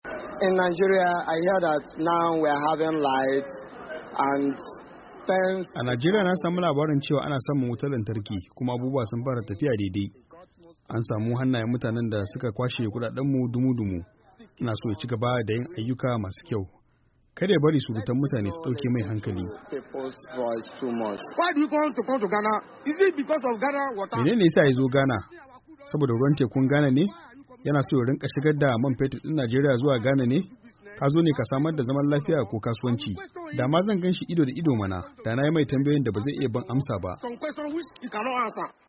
Wasu ‘yan Najeriya sun bayyana ra’ayoyinsu kan wannan ziyara inda wasu suka nuna farin ciki yayin da wasu kuma suka nuna rashin gamsuwarsu kan wannan ziyara, kamar yadda za ku ji a sautin da ke kasan wannan rahoto.